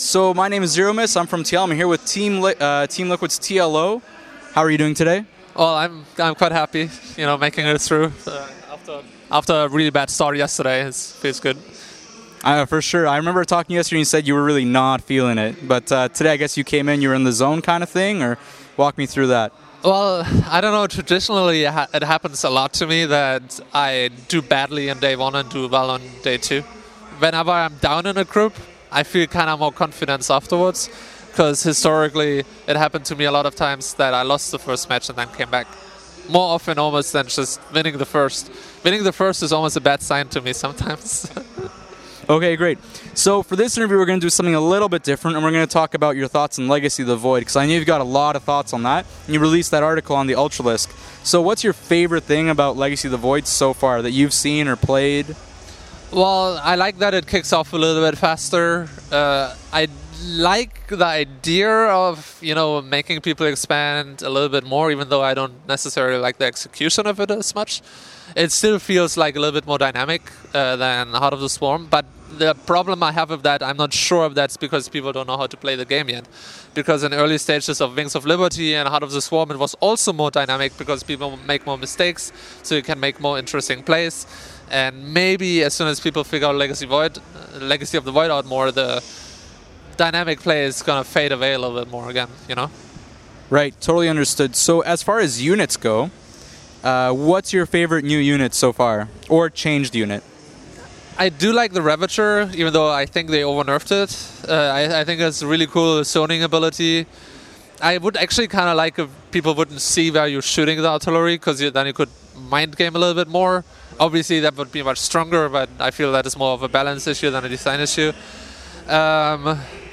Unfortunately there were some issues with the video so we've turned it into an audio podcast.